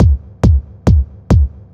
K-4 Kick.wav